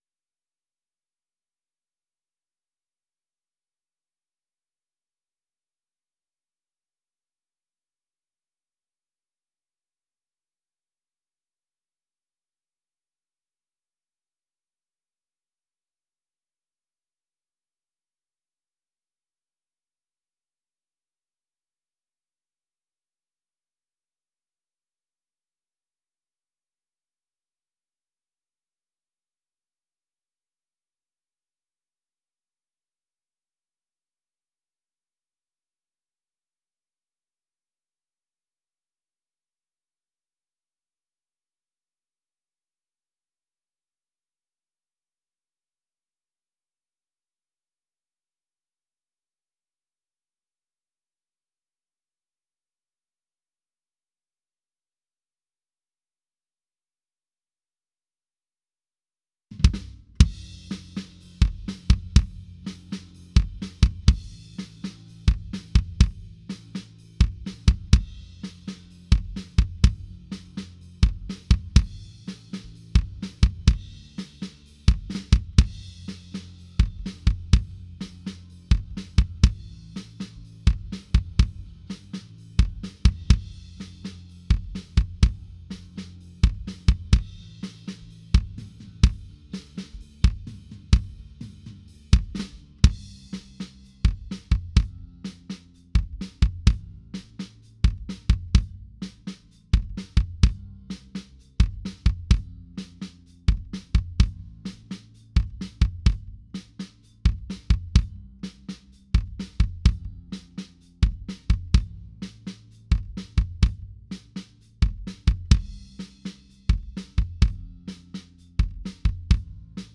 White KikCls.wav